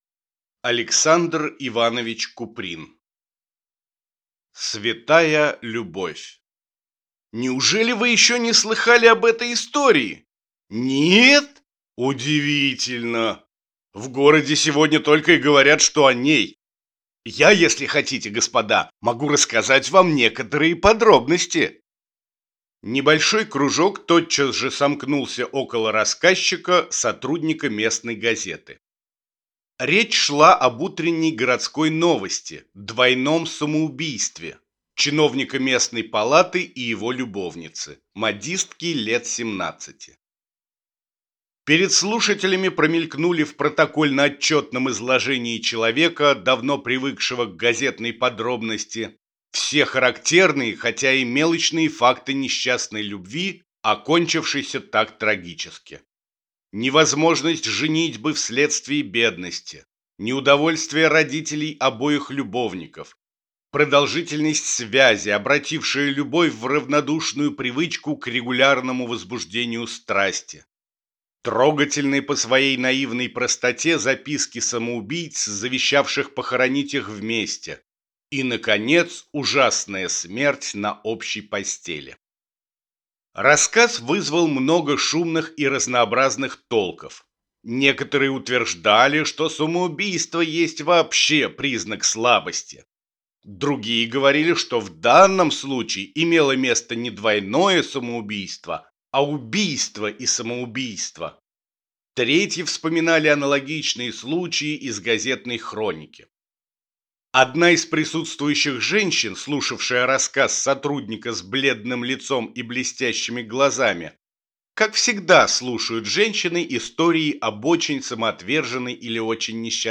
Аудиокнига Святая любовь | Библиотека аудиокниг
Прослушать и бесплатно скачать фрагмент аудиокниги